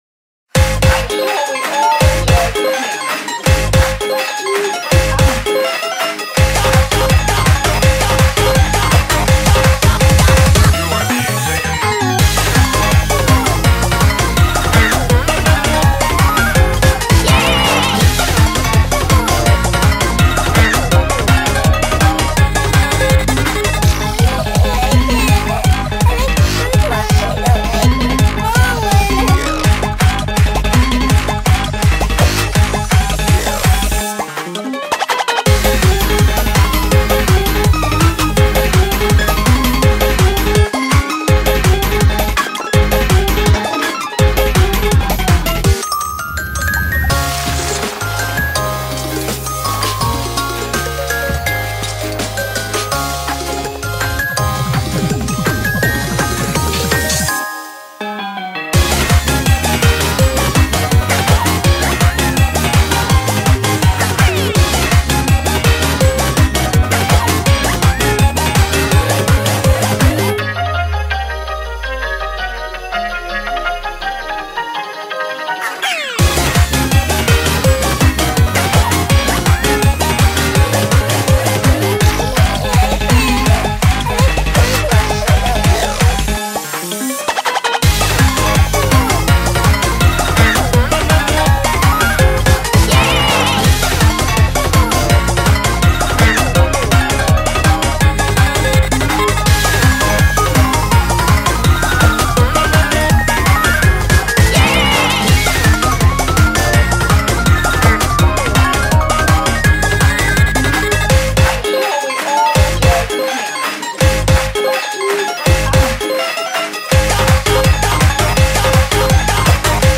BPM165
Audio QualityPerfect (Low Quality)